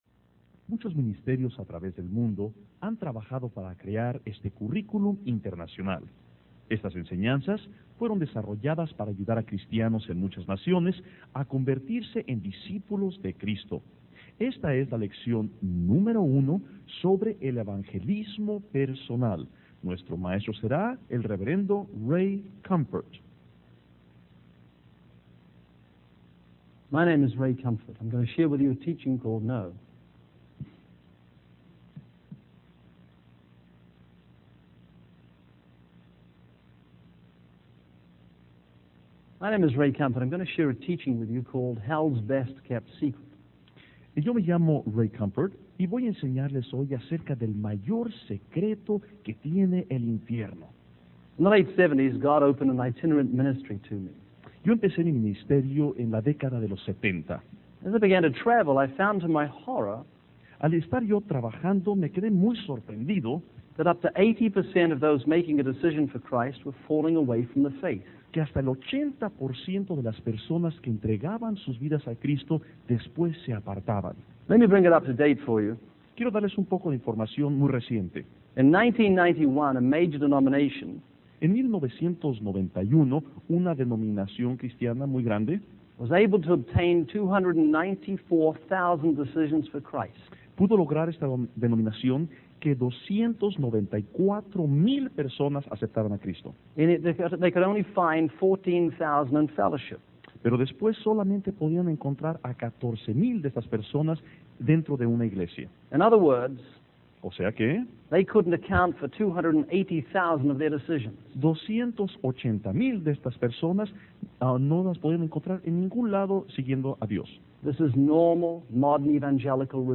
In this sermon, the preacher uses a story of two men on a plane to illustrate the difference between a gospel focused on life enhancement and a gospel focused on salvation.